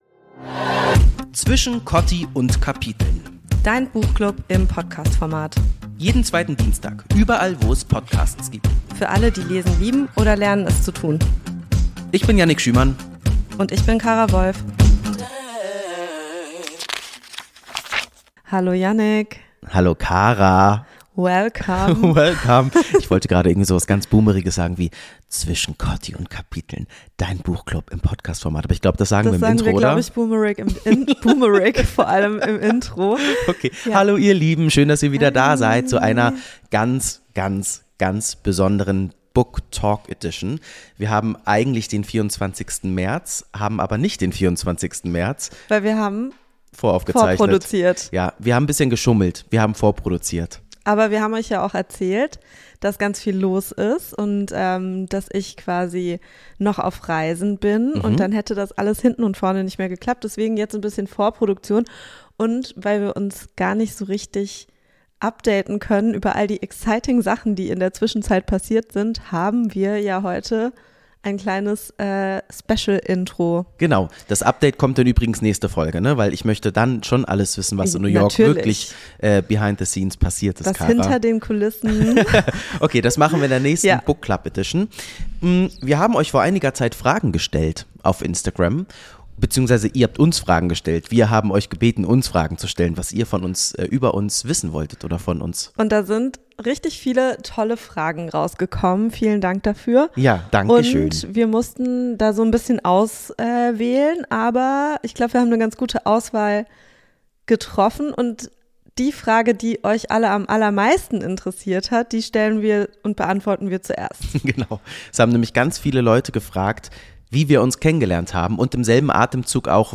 Book Talk Edition mit Jens Wawrczeck: Von Peter Shaw bis Hitchcock ~ Zwischen Kotti und Kapiteln Podcast